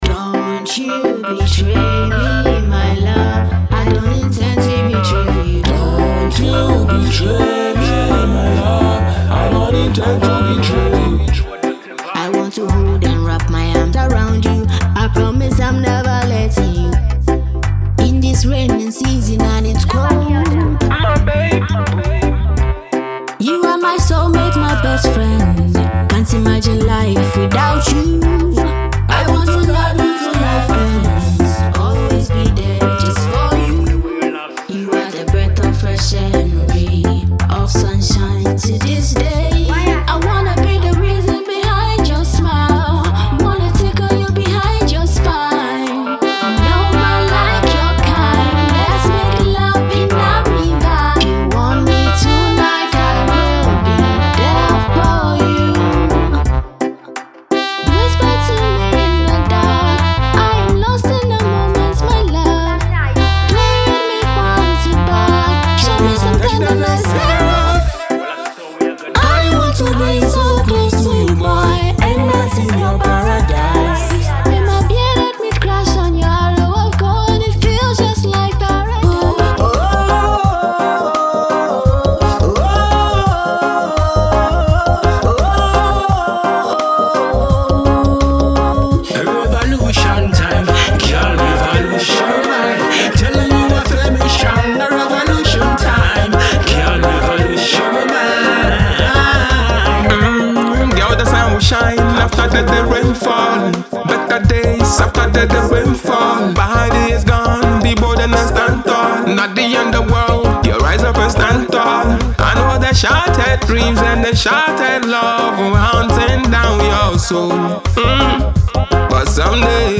Genre:Reggae
テンポは78～142 BPMで、魅力的なスウィング感、リラックスしたエネルギー、心地よいバイブレーションが満載です。
デモサウンドはコチラ↓
89 Male Vocal Loops
53 Female Vocal Loops